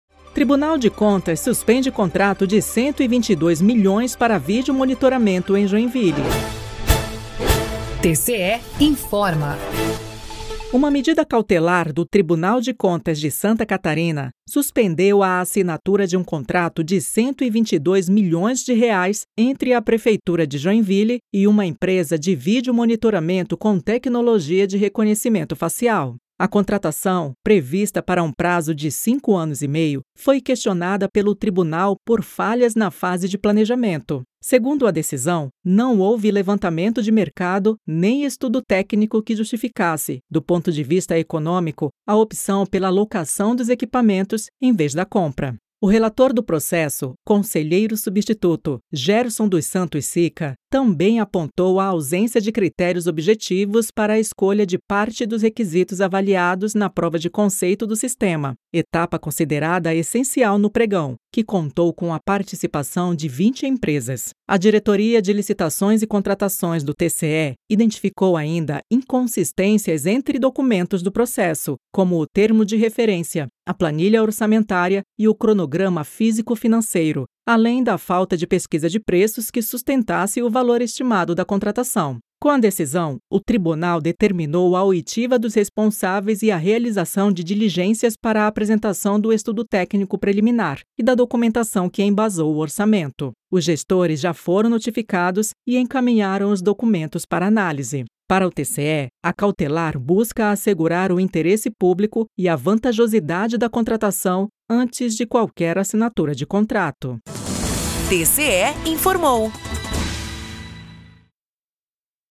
VINHETA TCE INFORMOU